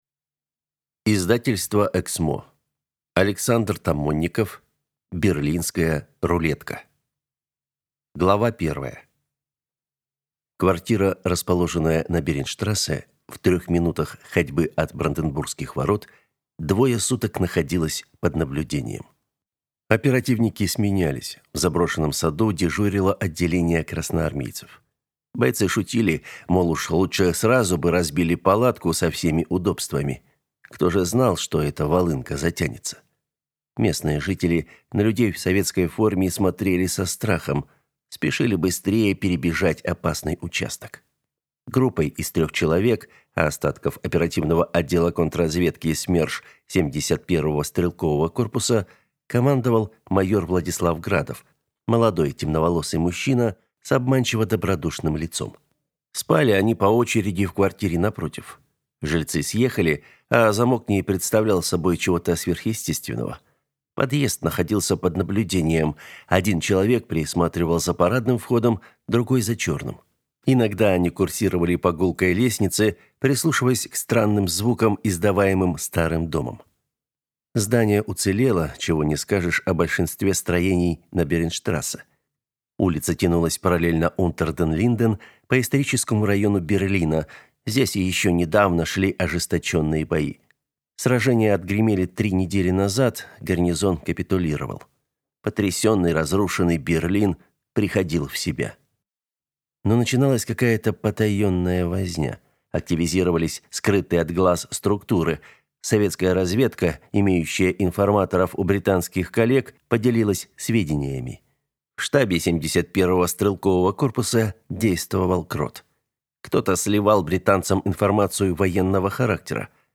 Аудиокнига Берлинская рулетка | Библиотека аудиокниг
Прослушать и бесплатно скачать фрагмент аудиокниги